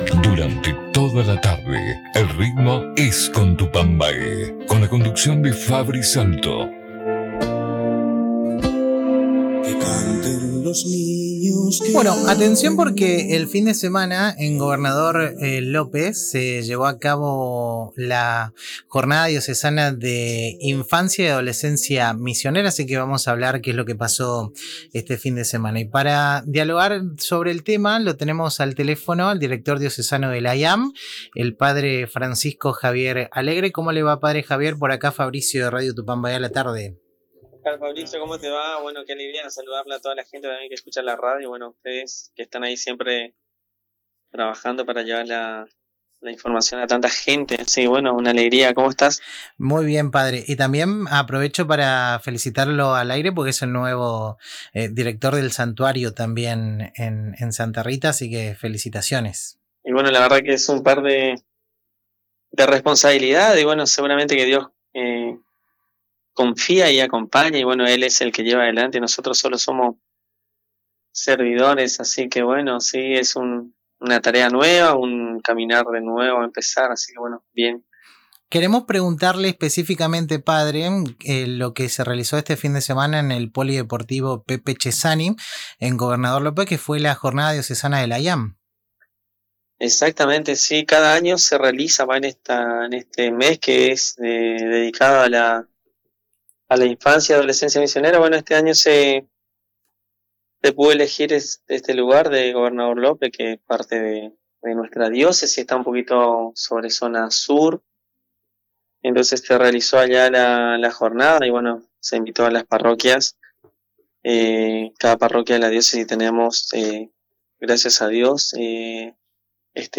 En diálogo con El Ritmo Sigue por Radio Tupambaé